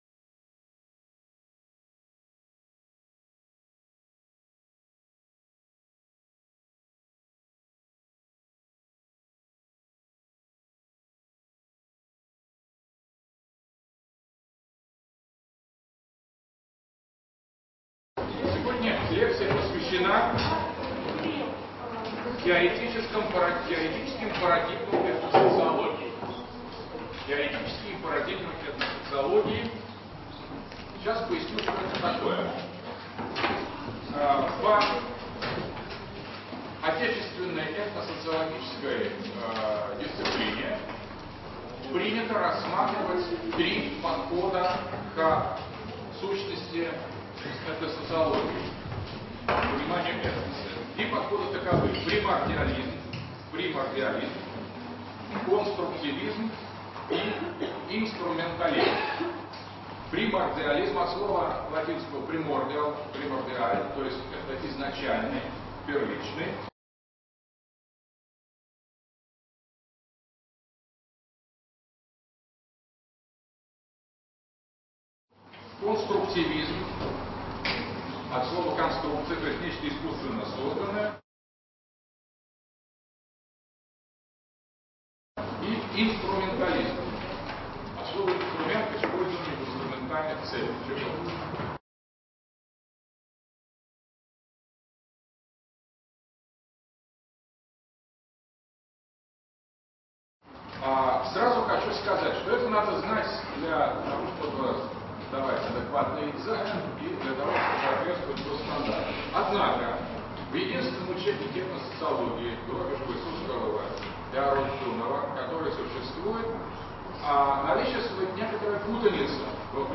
Этносоциология (2009). Лекция 3. Теоретические парадигмы этносоциологии
Примордиализм как основная социологическая модель понимания этноса. Баланс биологического и культурного начала в структуре этноса. Конструктивизм и инструментализм. Читает А.Г. Дугин. Москва, МГУ.